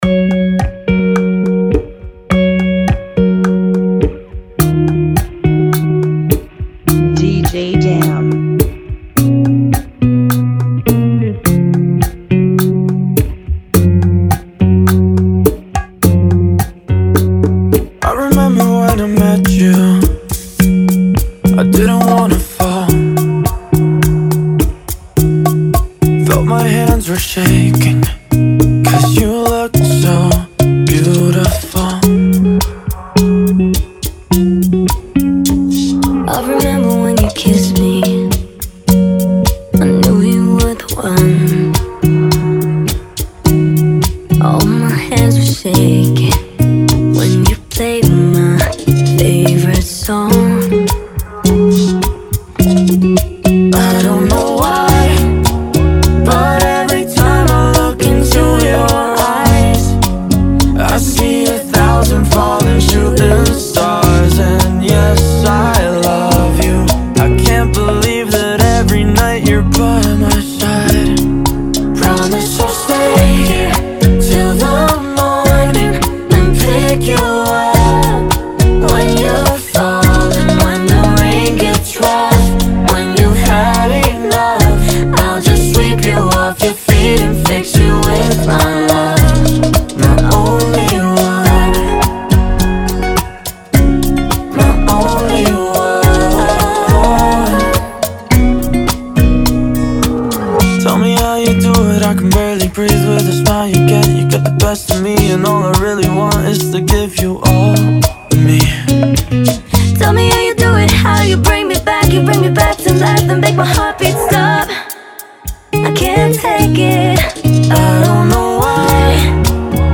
105 BPM
Genre: Bachata Remix